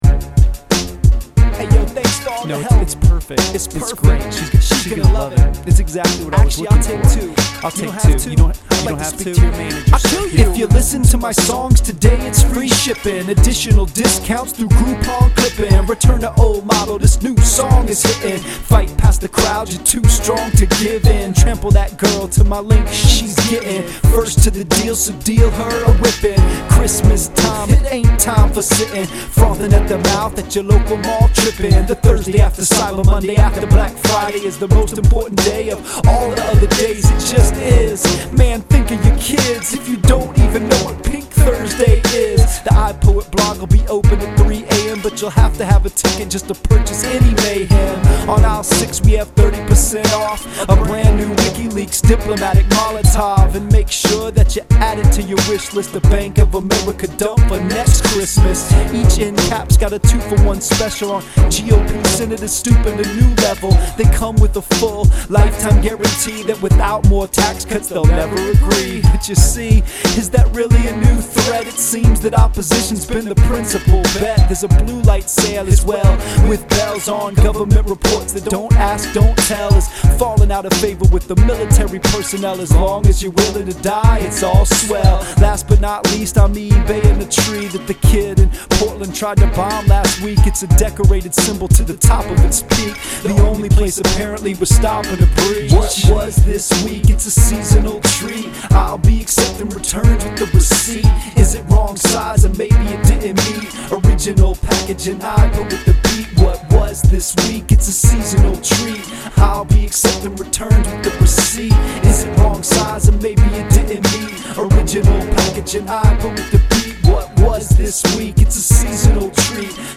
When the deepest discounts in rap news are available.